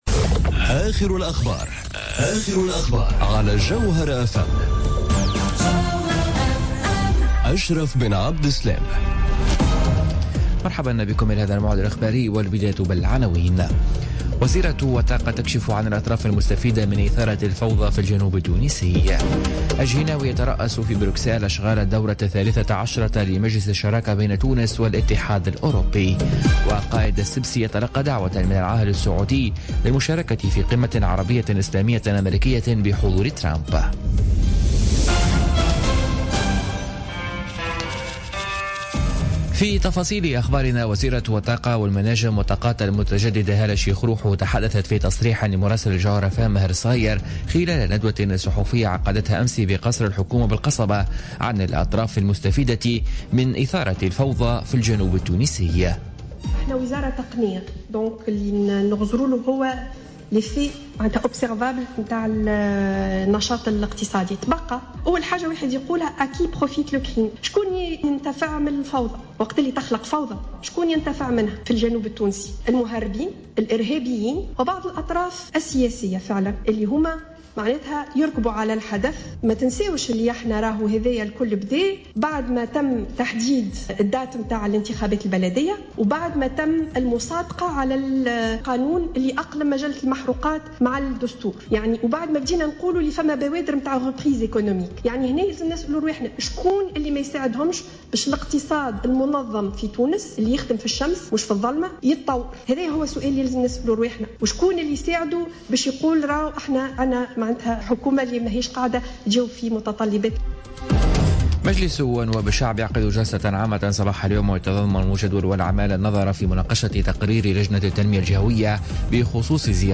نشرة أخبار منتصف الليل ليوم الثلاثاء 9 ماي 2017